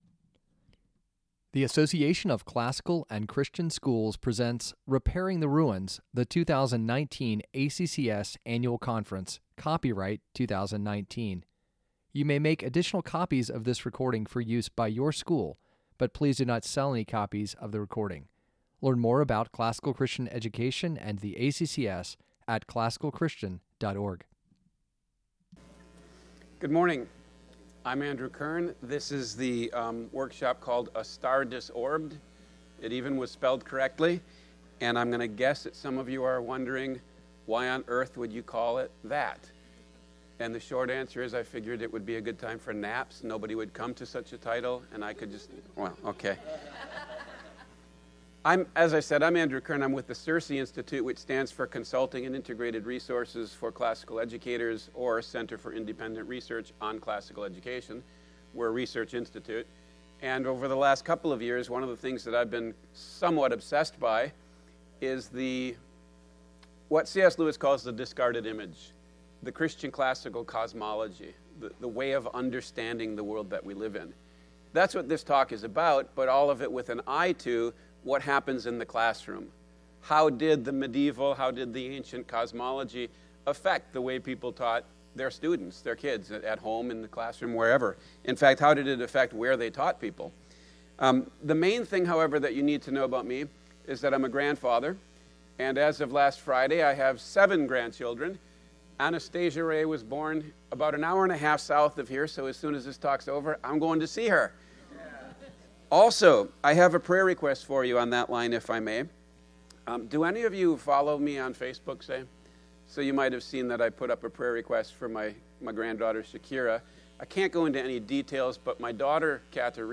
2019 Workshop Talk | 01:04:11 | All Grade Levels, Culture & Faith
Additional Materials The Association of Classical & Christian Schools presents Repairing the Ruins, the ACCS annual conference, copyright ACCS.